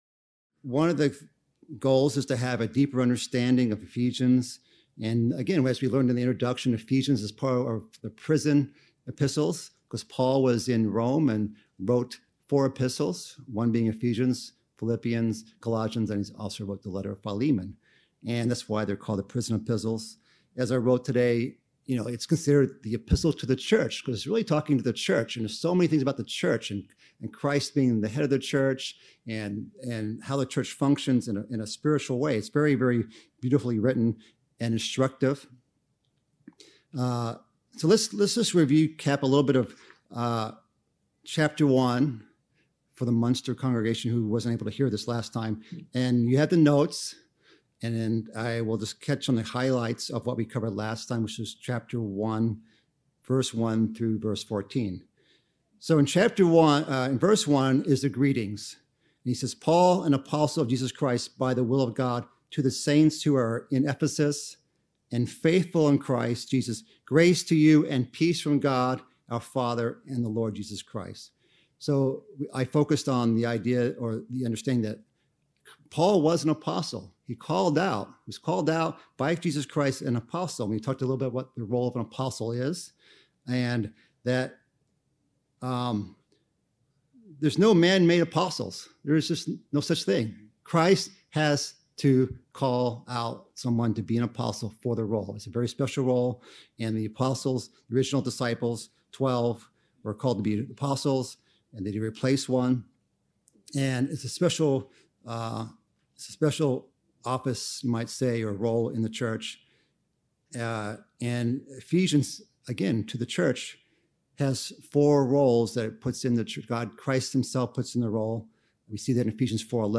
Bible Study - Ephesians